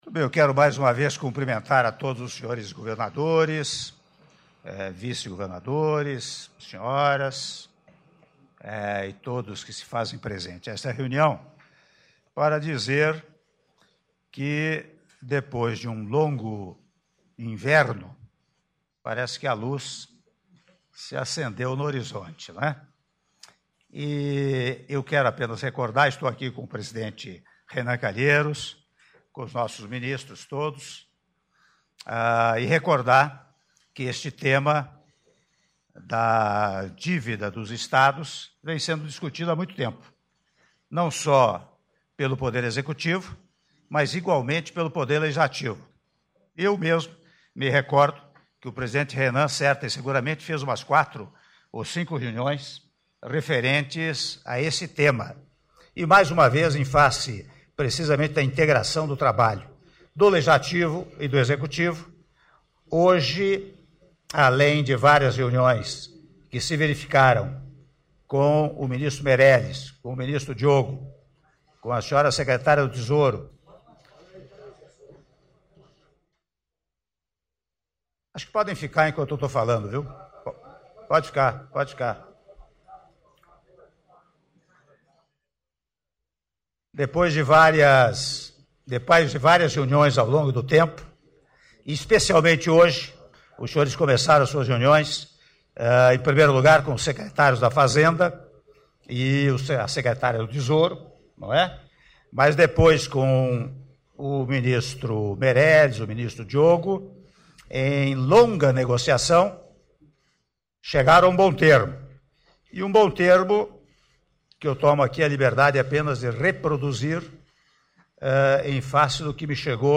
Áudio da fala do Presidente da República em exercício, Michel Temer, durante reunião com governadores das unidades federativas do Brasil - Brasília/DF (07min11s)